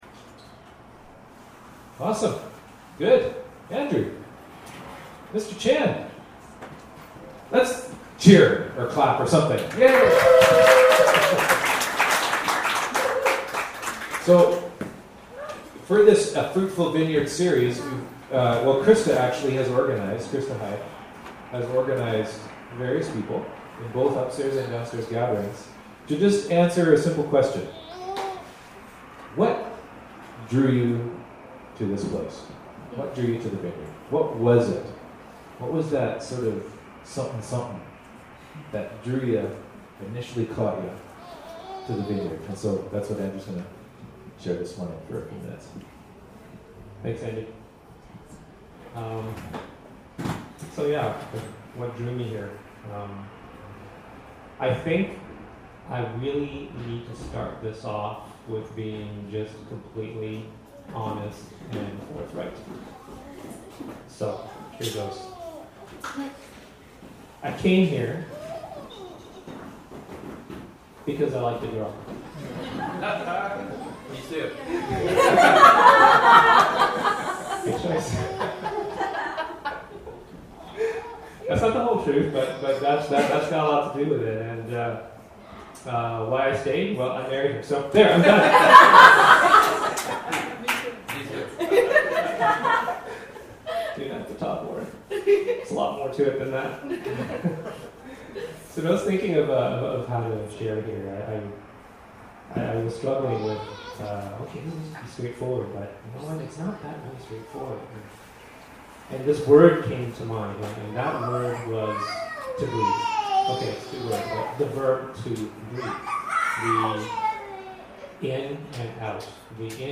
Service Type: Upstairs Gathering